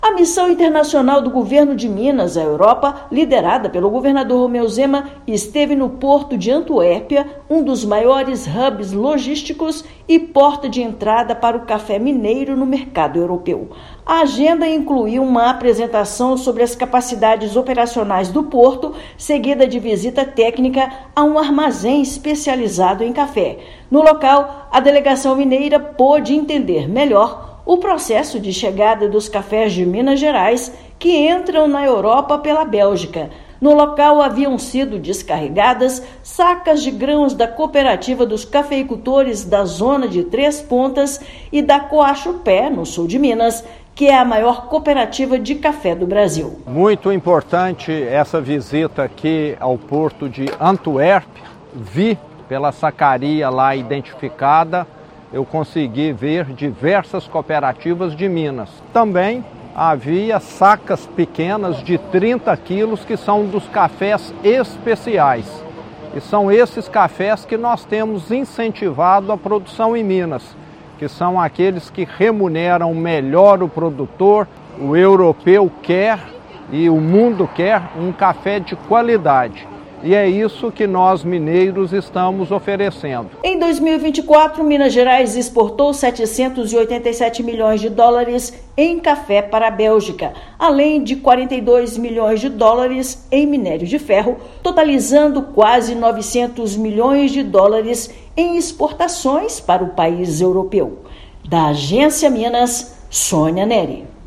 [RÁDIO] Minas Gerais estreita parcerias logísticas e comerciais durante vistoria ao Porto de Antuérpia-Bruges, na Bélgica
Agenda reforça o papel estratégico do porto belga na competitividade do agronegócio mineiro, especialmente no segmento de cafés especiais. Ouça matéria de rádio.